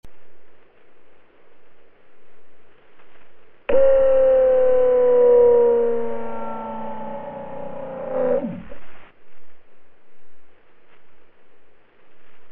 They collected approximately 24 hours of uninterrupted directional acoustic data throughout the float operations, including diving and parking at depth.
Most of the data represented ambient noise, but the occasional boat sound was also captured.
Panels on left show an example of boat noise at ~3:30 a.m. PT, while those on the right show helicopter sounds at 9:58 a.m. PT (both on July 27).
Audio clips were truncated to cut out ambient noise.
331boat.mp3